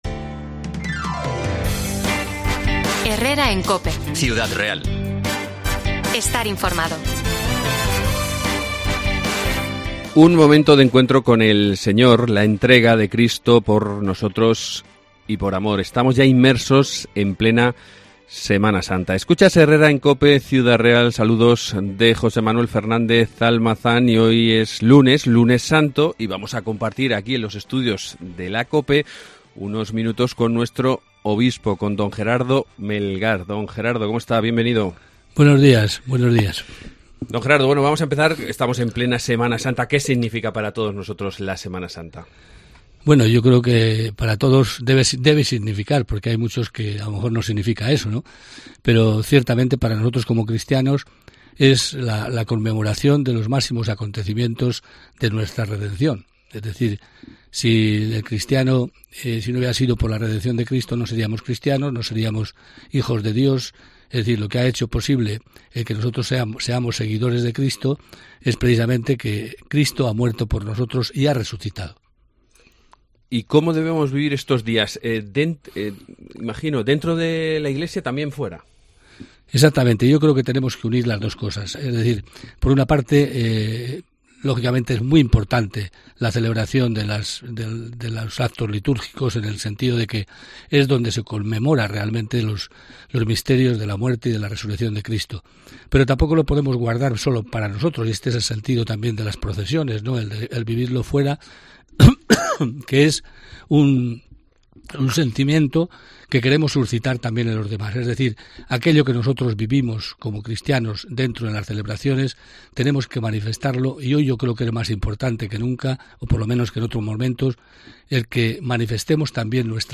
Entrevista con Don Gerardo Melgar, Obispo de Ciudad Real